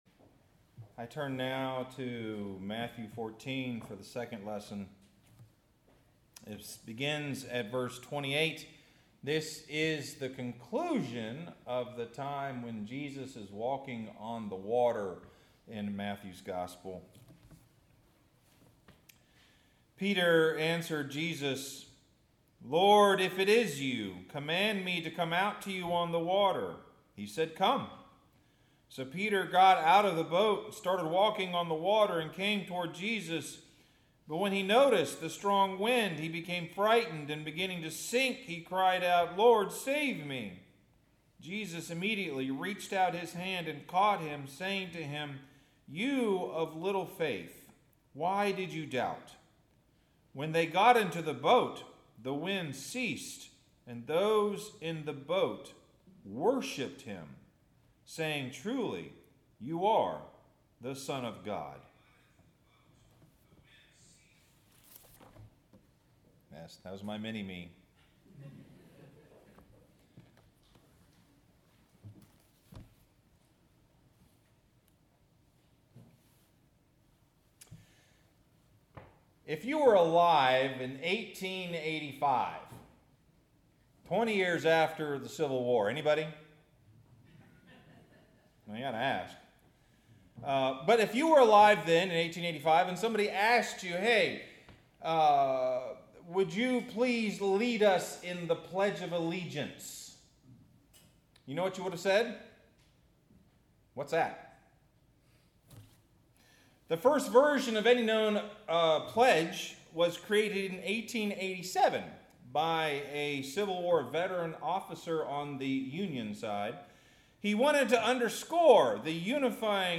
Sermon – Our Greatest Allegiance